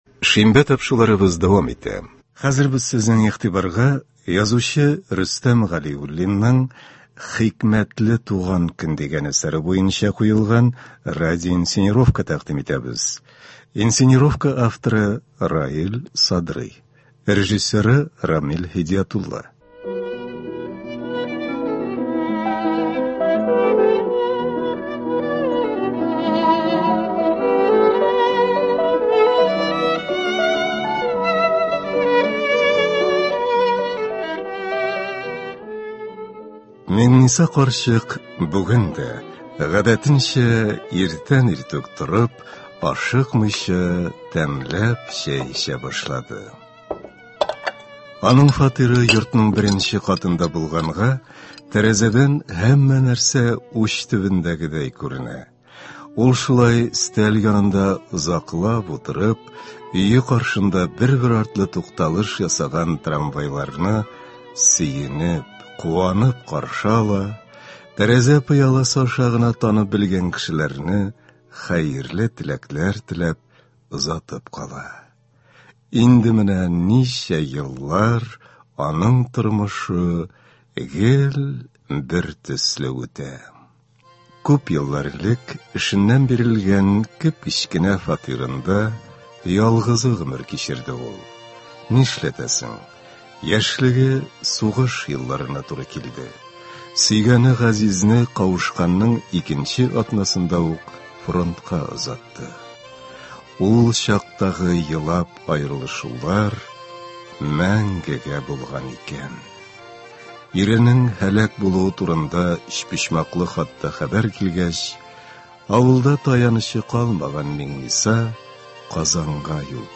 Радиоинсценировка (10.08.24) | Вести Татарстан